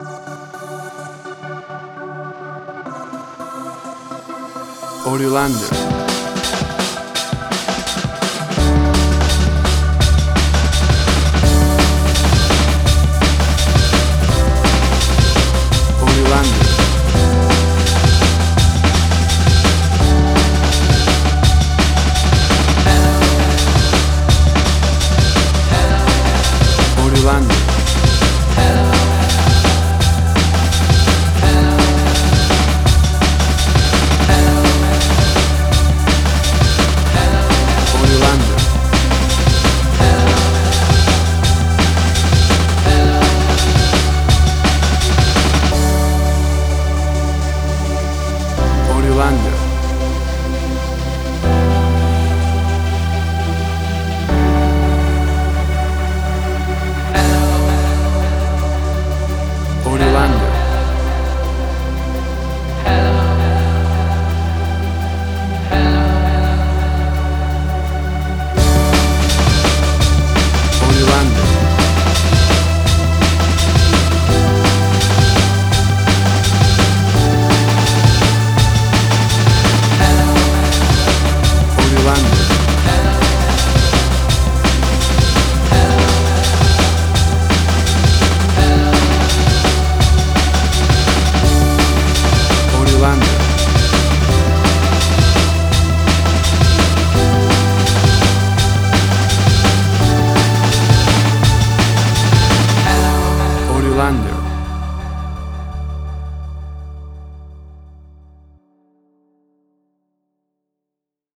Suspense, Drama, Quirky, Emotional.
Tempo (BPM): 168